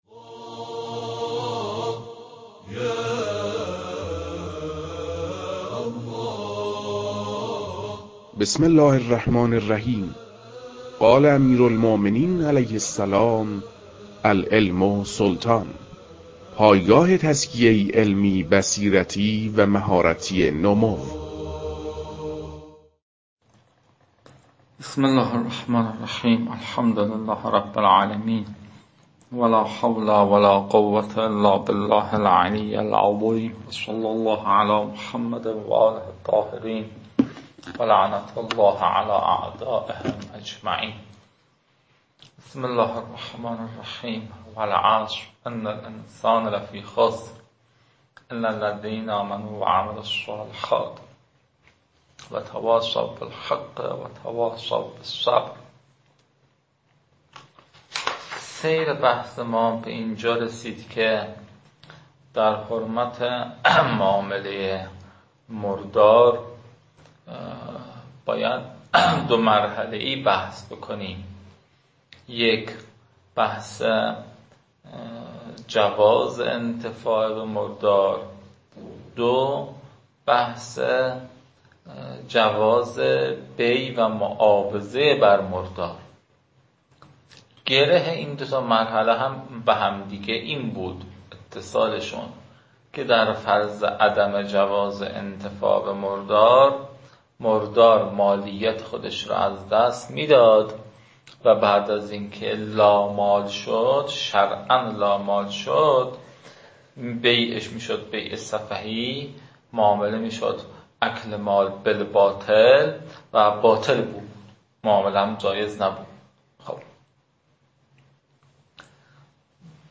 فایل های مربوط به تدریس بخش نخست كتاب المكاسب متعلق به شیخ اعظم انصاری رحمه الله (مکاسب محرّمه)